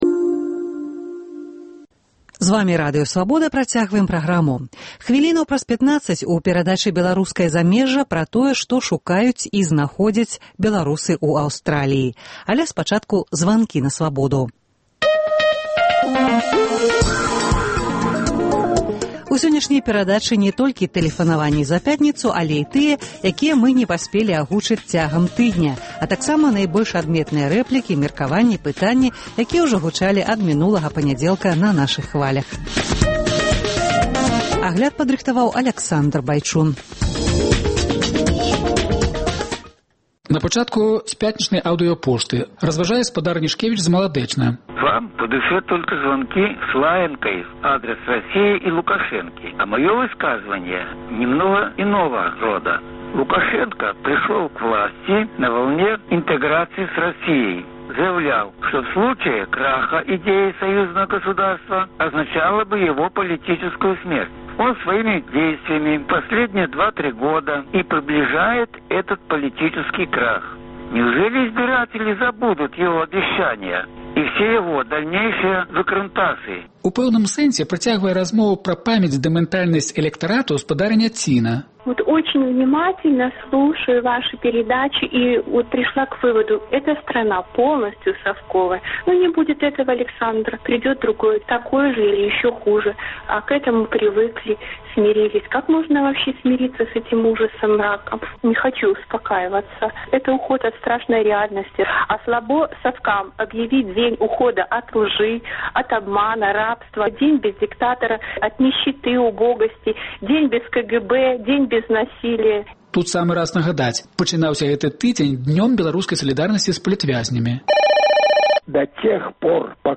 Тыднёвы агляд званкоў ад слухачоў "Свабоды"